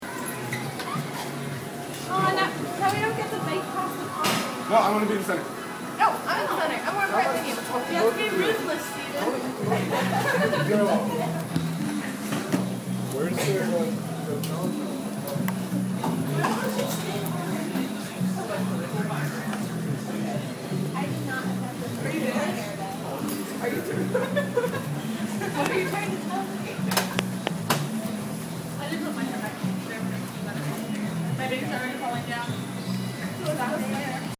Field recording #2
sound of sneakers on the gym floor, music over the loud speakers, the people on the machines next to me, my breathing